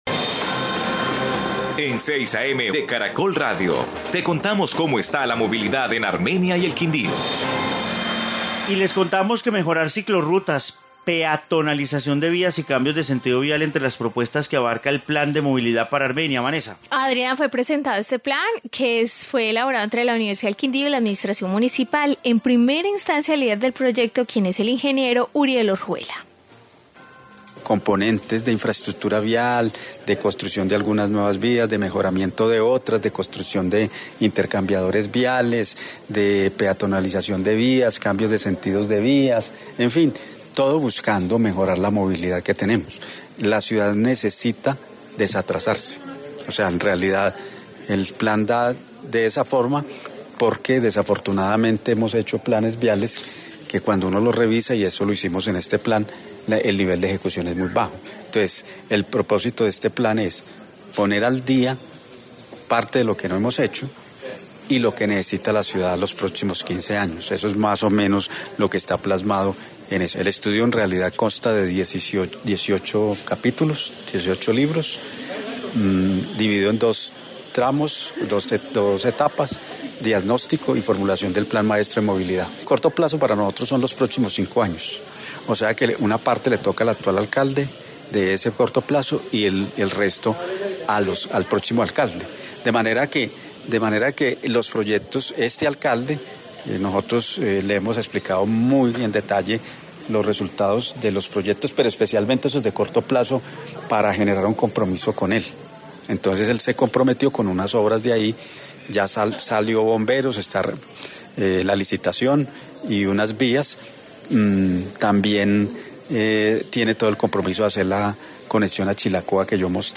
Informe plan de movilidad